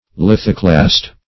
Search Result for " lithoclast" : The Collaborative International Dictionary of English v.0.48: Lithoclast \Lith"o*clast\ (l[i^]th"[-o]*kl[a^]st), n. [Litho- + Gr. kla^n to break.]